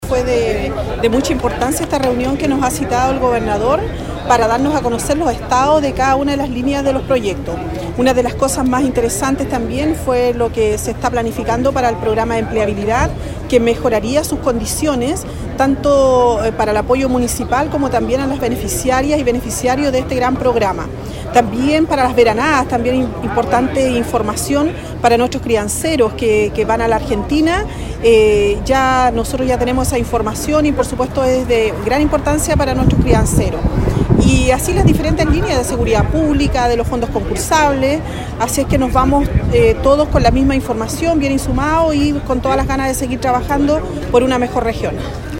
Para la alcaldesa Carmen Juana Olivares, esta reunión fue de relevancia para conocer las iniciativas para las distintas comunas de la región.